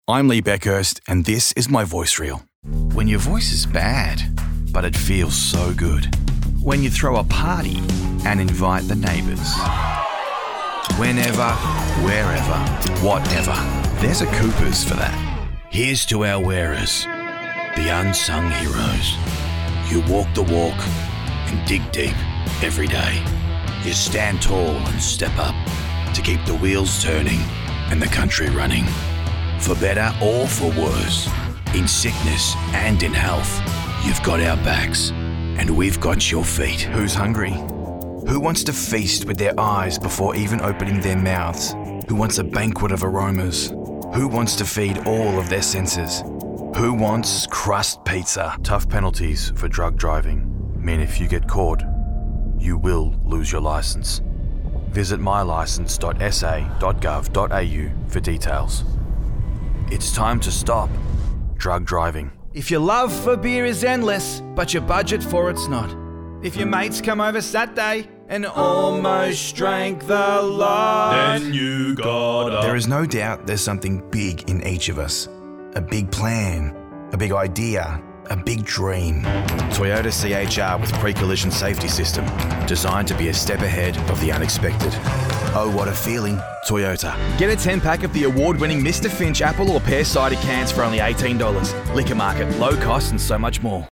Compilation
VOICEOVER Artist Profile